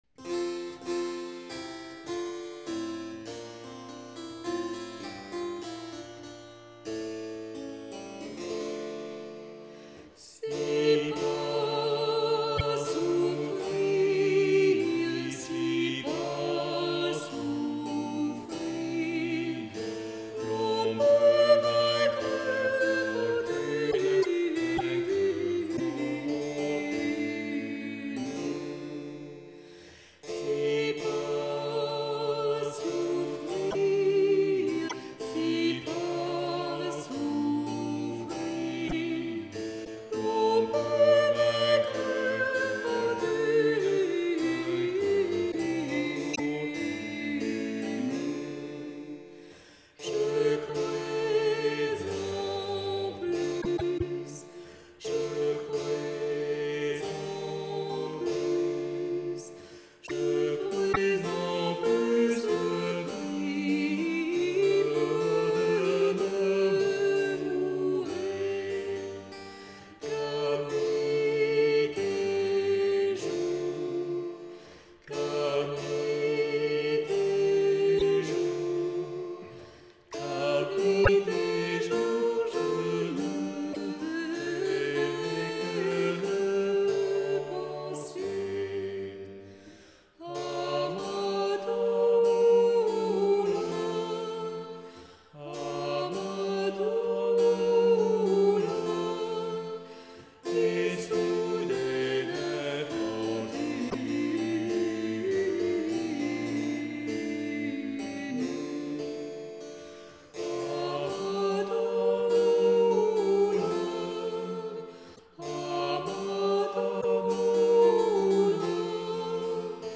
Musique ancienne, Renaissance et baroque
Luth
Flûtes à bec
Percussions
Violes
Violon
Clavecin